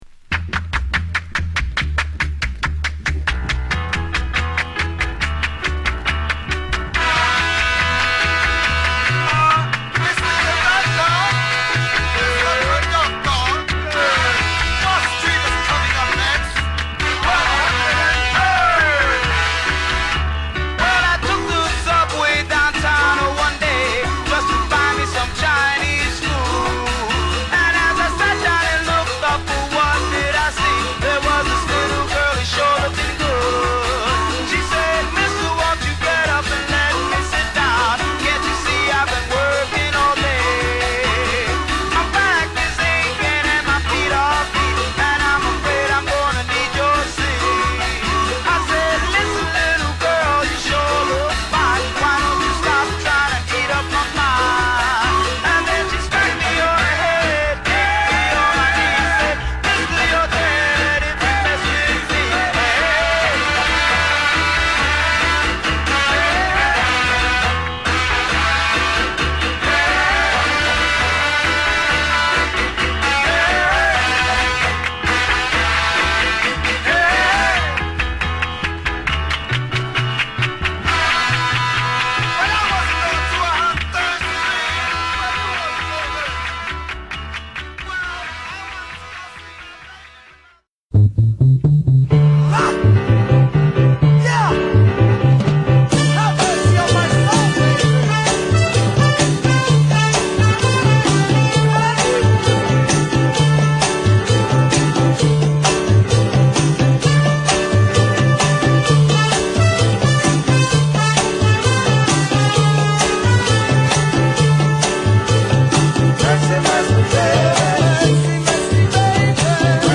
シールド品の為、Youtubeから録音しています。